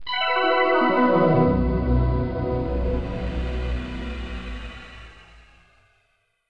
OS3 Warp 1.0 Shutdown.wav